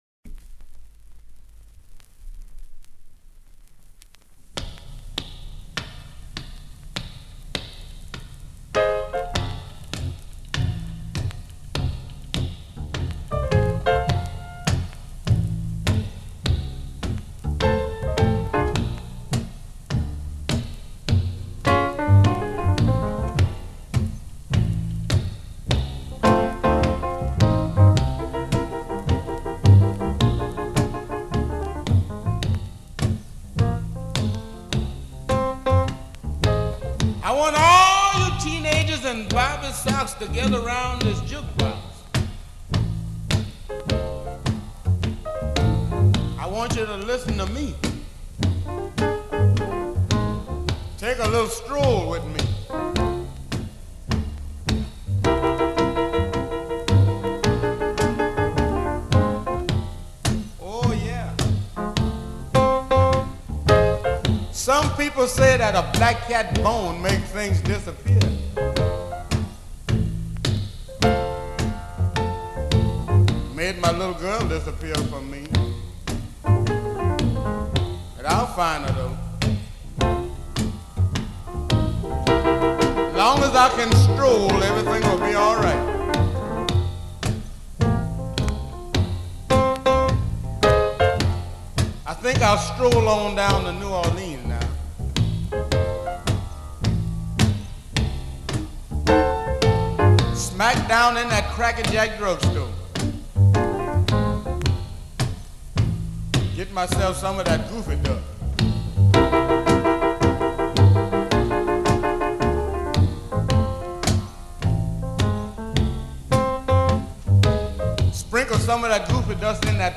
Quartet
• URBAN BLUES (BLUES)
• Vocal
• Piano
• Guitar
• Bass
• Drums